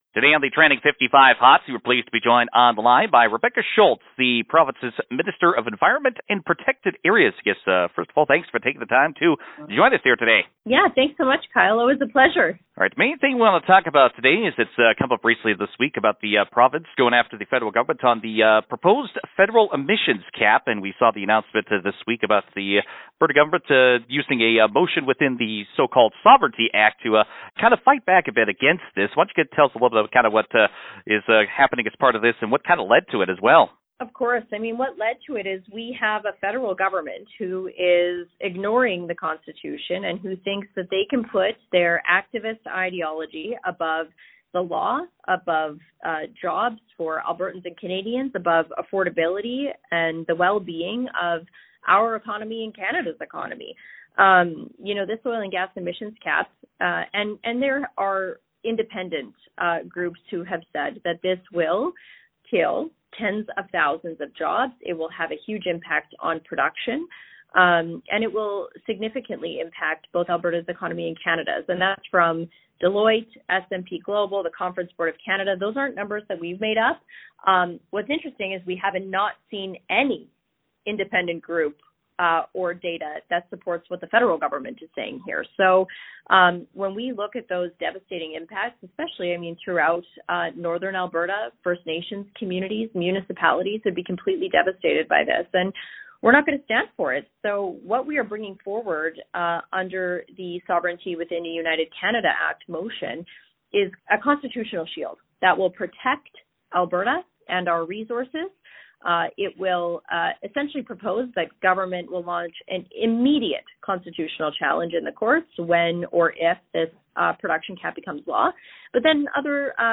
Alberta’s Environment Minister, Rebecca Schulz, says concerns around the effects on the province’s economy is leading to this action being taken.